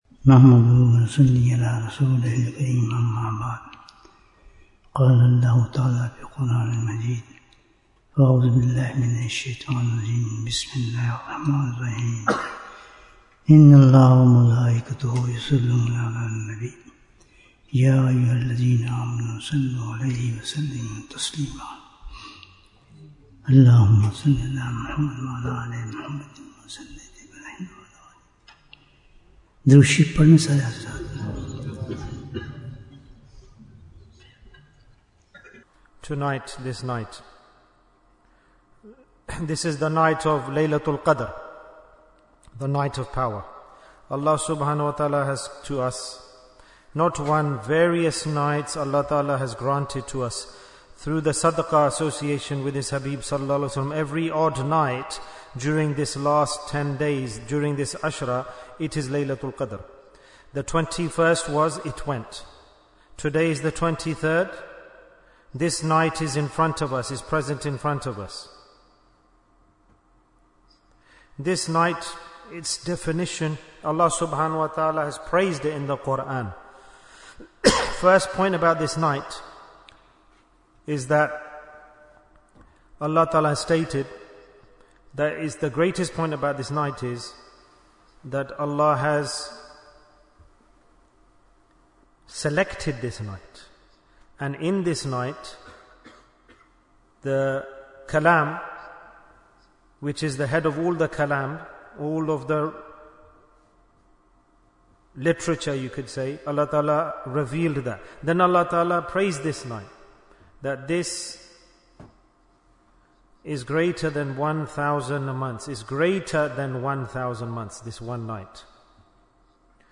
Jewels of Ramadhan 2025 - Episode 31 - Layla-tul-Qadr in Burnley Bayan, 43 minutes22nd March, 2025